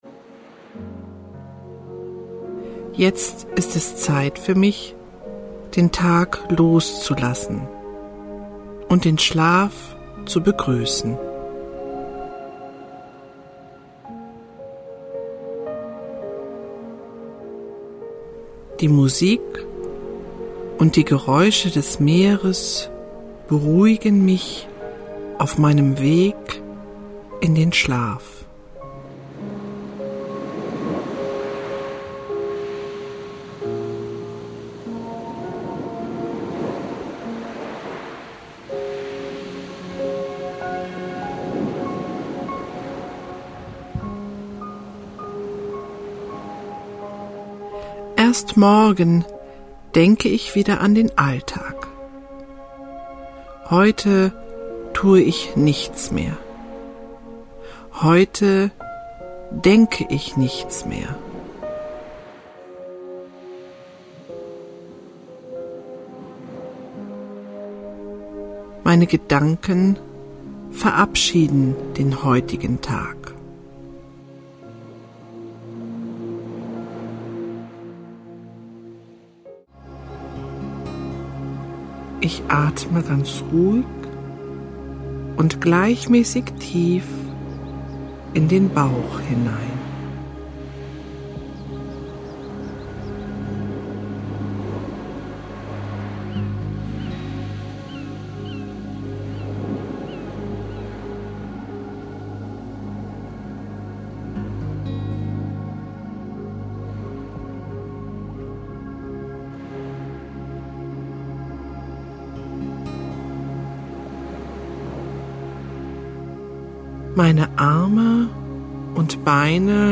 Hörbuch kaufen:
Die Naturklänge (Meeresrauschen, Bachplätschern) und die Musik sind sorgsam nach den neuesten Erkenntnissen der Musikwissenschaft zusammengestellt. Sie wirken wohltuend und beruhigend gleichsam. Die Stimmung ist auf den natürlichen Kammerton A in 432 Hz eingestellt.